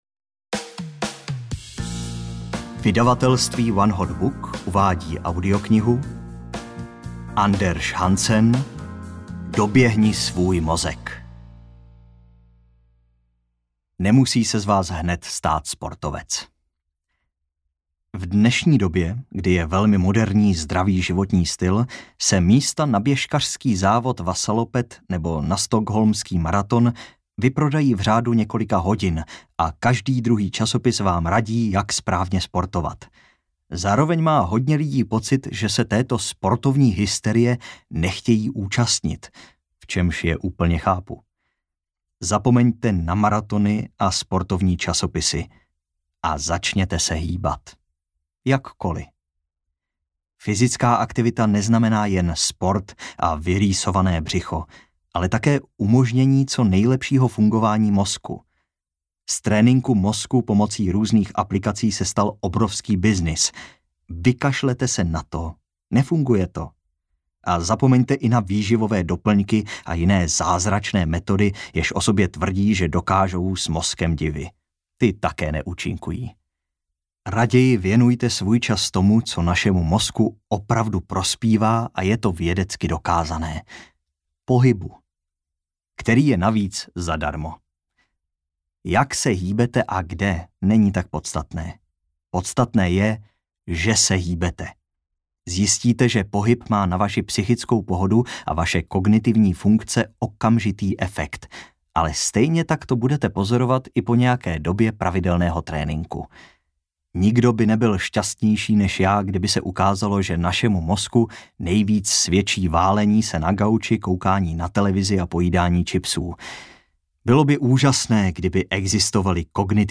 Doběhni svůj mozek: Jak cvičení a pohyb prospívají mozku audiokniha
Ukázka z knihy